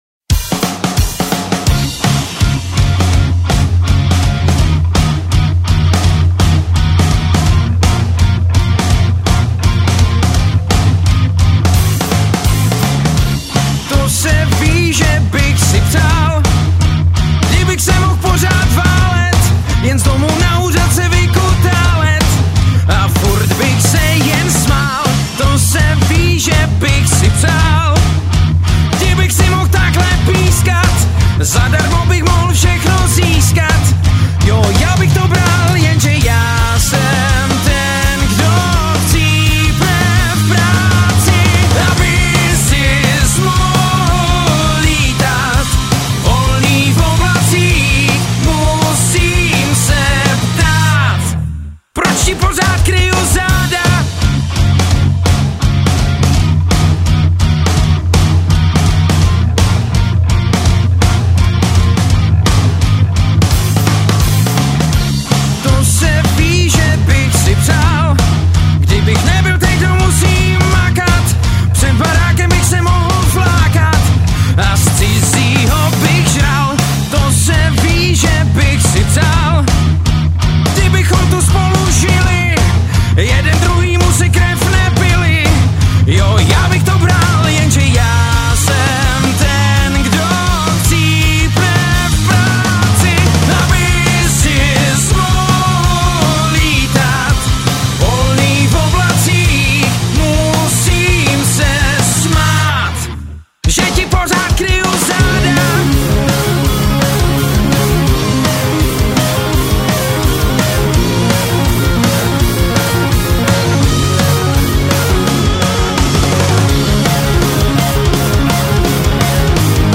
Žánr: Rock
Rocková muzika s prvky popu.